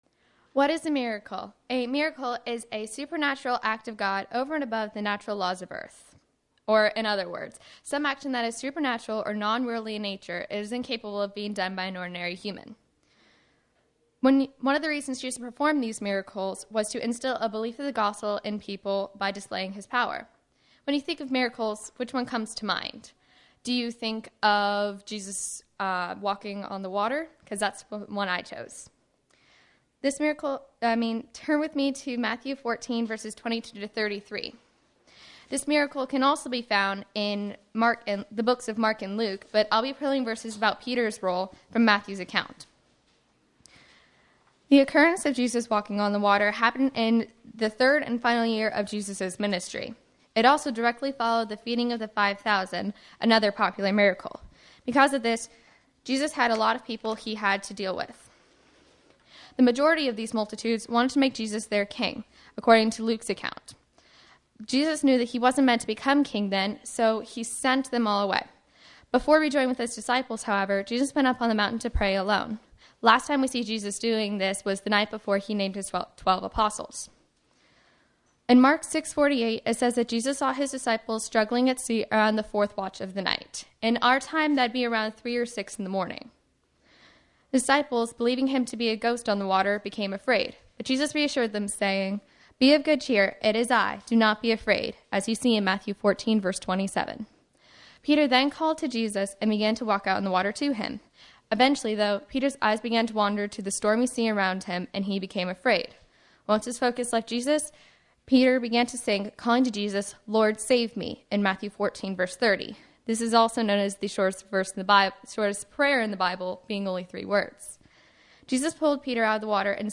What Is a Miracle? (Ladies' Class)